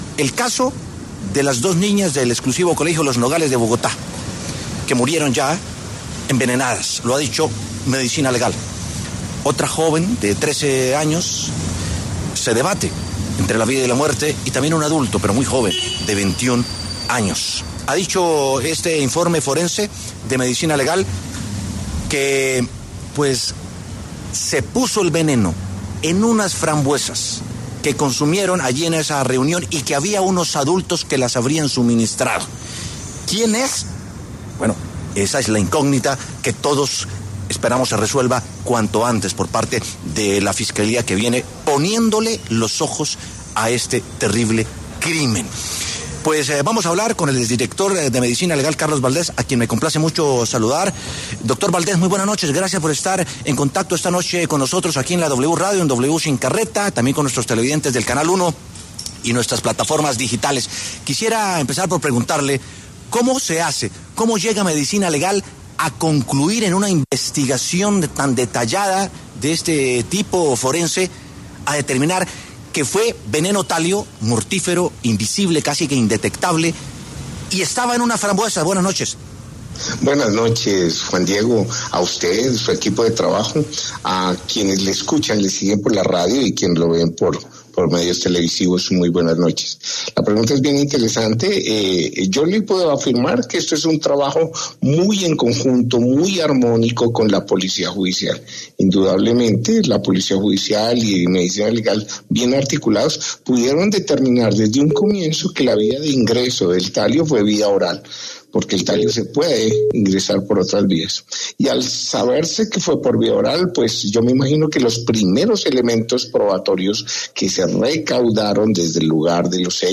A propósito de esto, W Sin Carreta conversó con Carlos Eduardo Valdés, exdirector de Medicina Legal, para conocer cuál es el proceso de esta entidad para llegar a esta conclusión.